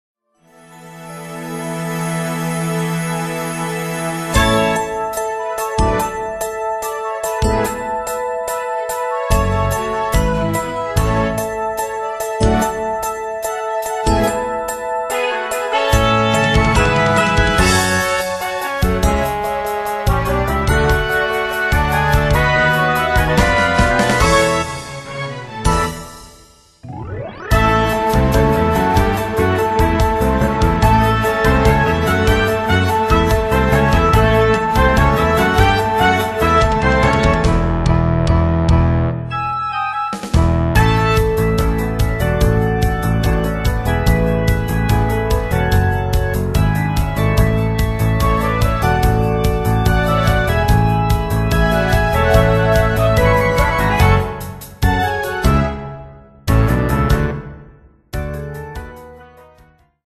Category: Broadway, Film and Shows